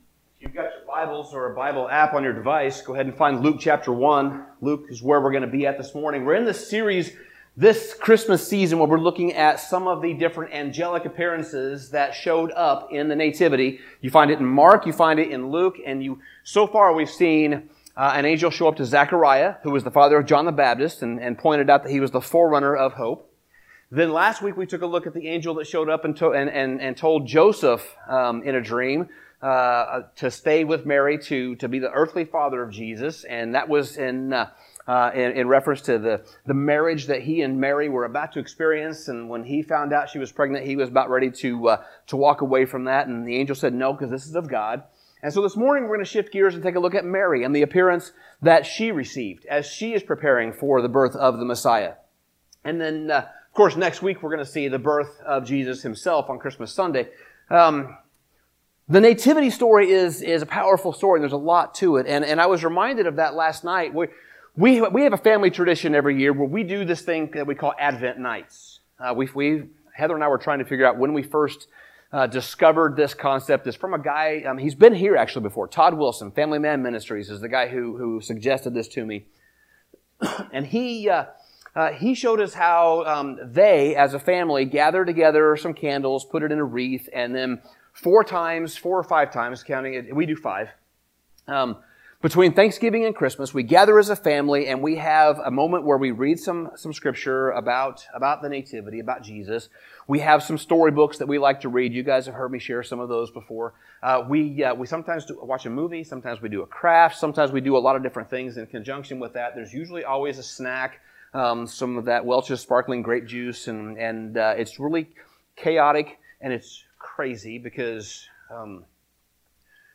Hope Is Born: The Certainty Of Hope | Parkview Christian Church